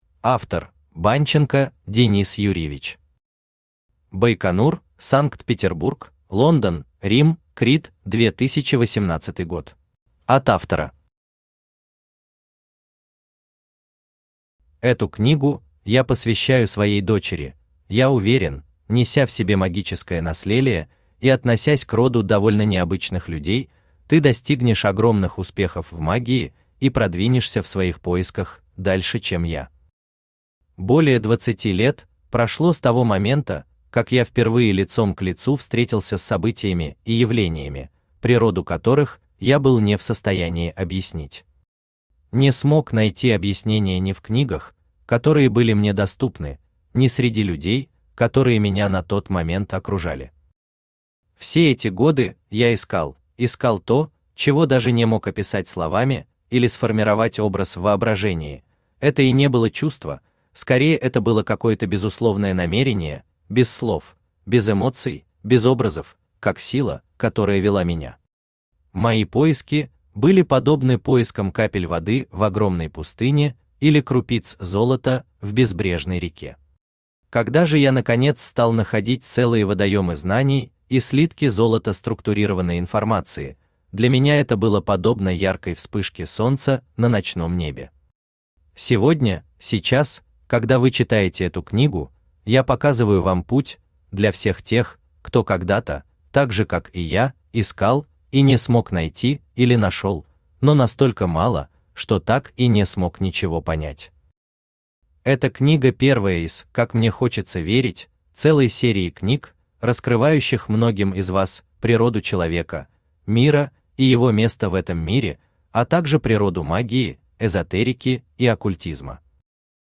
Аудиокнига Краткий курс по гаданию на картах Таро для начинающих | Библиотека аудиокниг
Aудиокнига Краткий курс по гаданию на картах Таро для начинающих Автор Денис Юрьевич Банченко Читает аудиокнигу Авточтец ЛитРес.